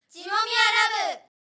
サウンドロゴ（WAV：550KB）